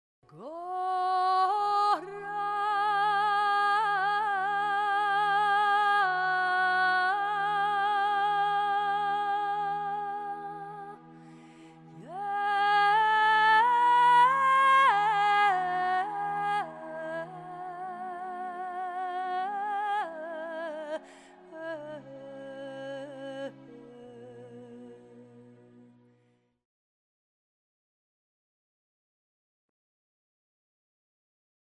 You are here: Home » Lehre » Hörbeispiele (Musikalische Akustik) » 0212flanger_original.mp3 » View File
0212flanger_original.mp3